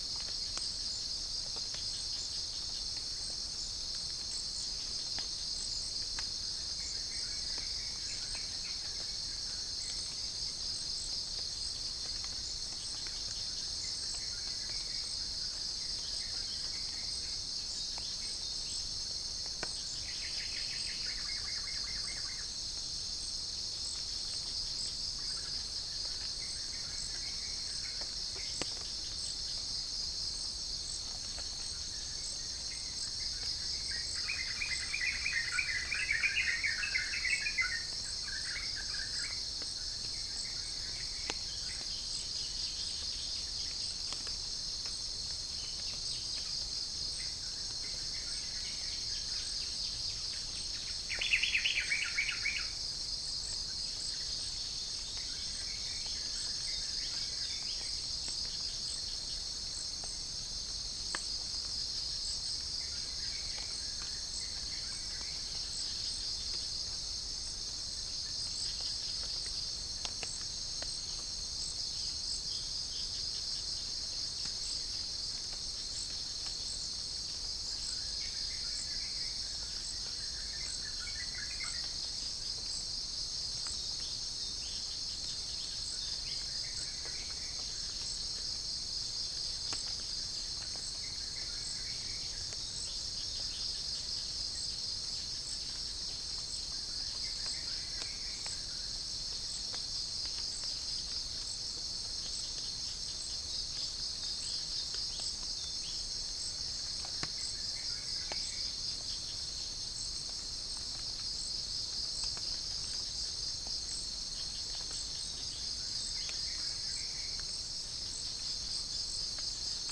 Pycnonotus goiavier
Orthotomus sericeus
Orthotomus ruficeps
Rhipidura javanica